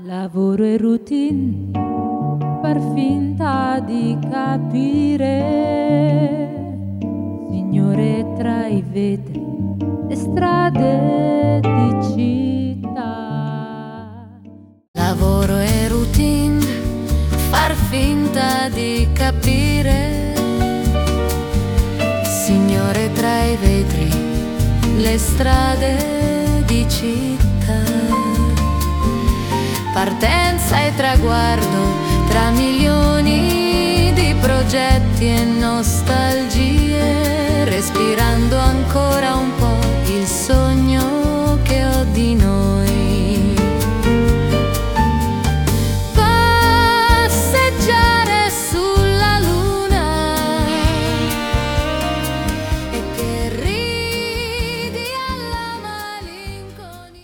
From piano/vocal demo → AI arrangement + vocals.
• Start: original (piano & vocals)
• 00:15: new arrangement + AI vocals